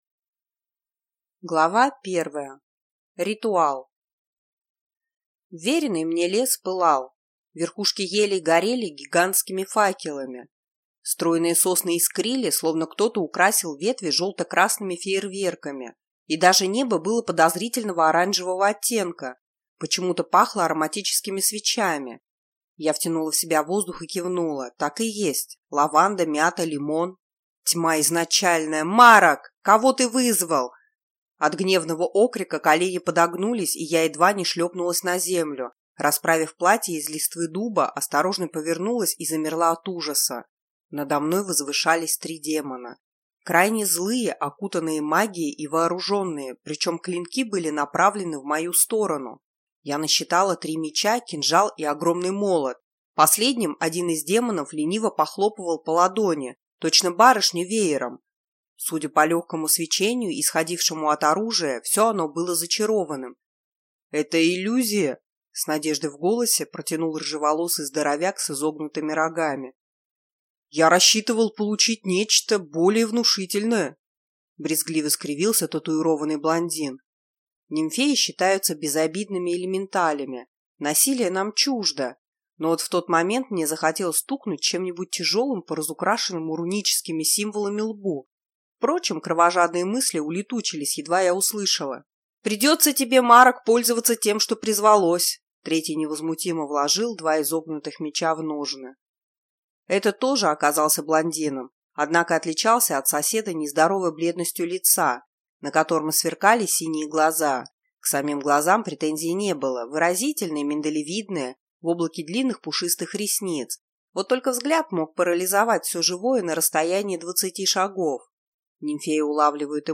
Аудиокнига Аккад ДЭМ и я. Призванная | Библиотека аудиокниг